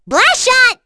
Cecilia-Vox_Skill5_short_b.wav